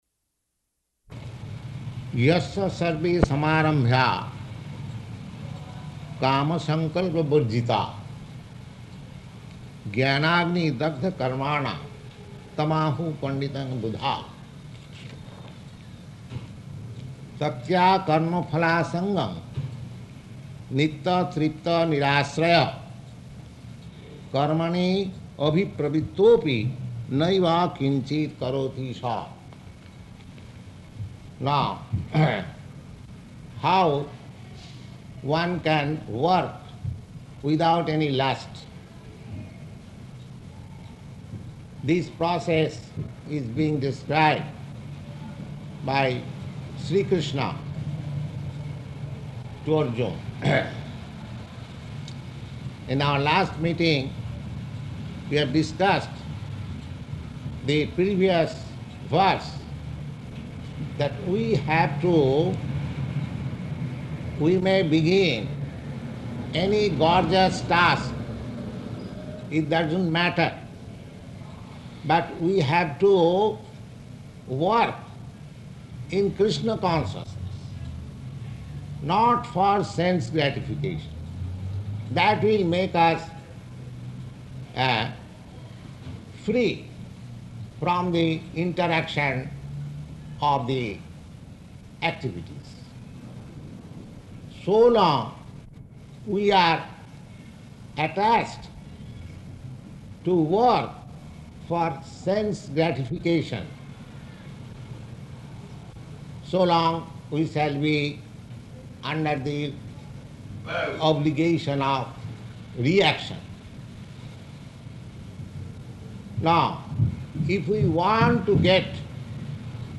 Type: Bhagavad-gita
Location: New York